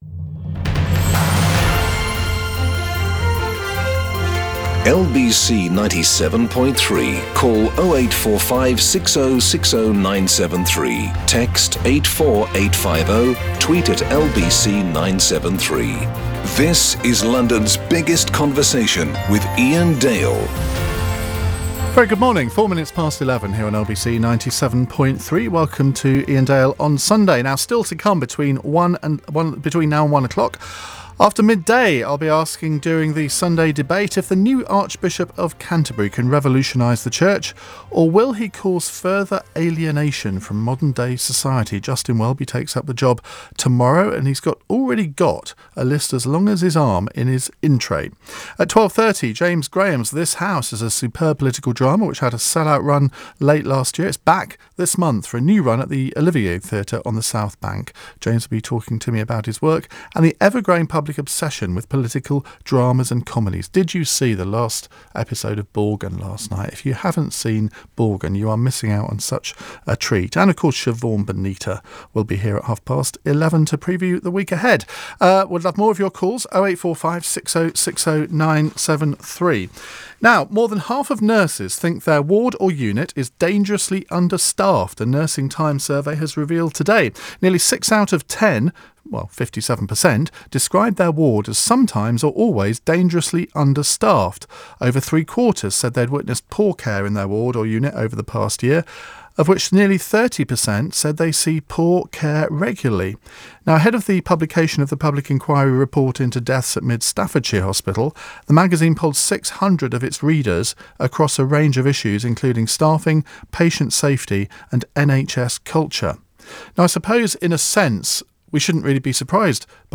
LBC radio